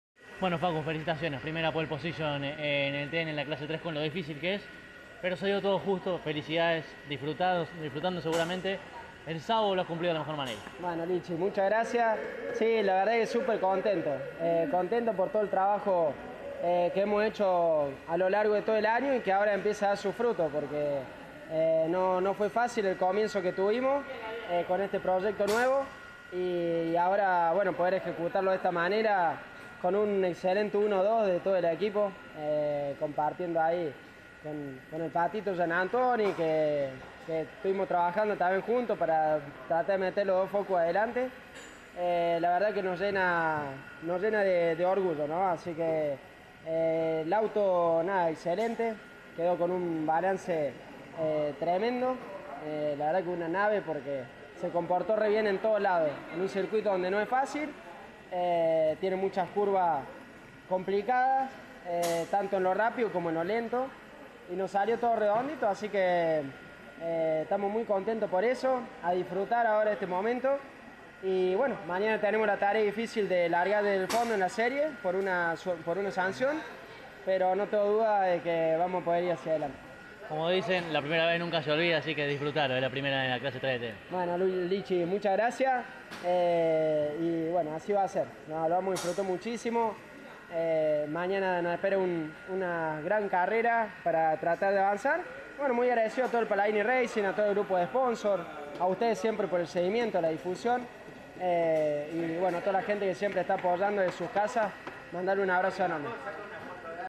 El testimonio del poleman